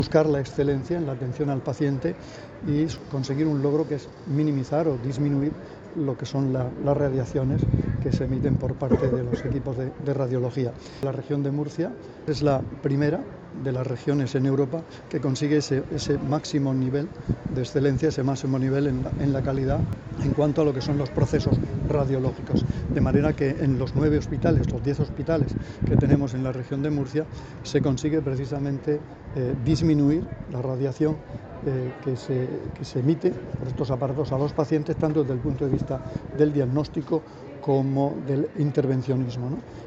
Declaraciones del consejero de Salud sobre la certificación  de máximo nivel de seguridad en procesos sanitarios de radiación que ha conseguido la Región